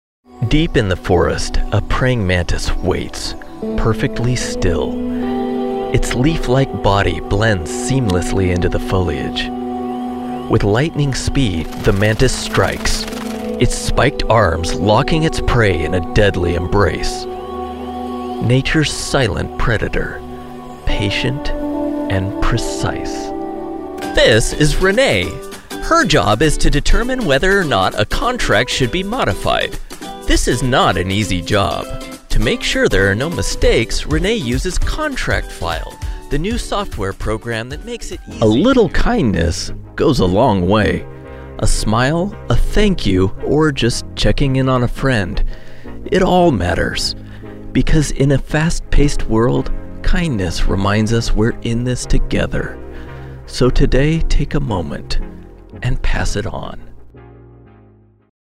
Full-Time Spanish Voice Actor.
My voice is professional, upbeat, enthusiastic, sensual, fresh, warm, narrator, instructive, clear, corporate, friendly, strong, likable, happy, informative, conversational, animated, natural, articulate, versa...